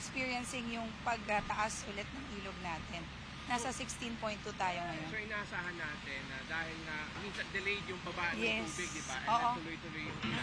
sample_interview.wav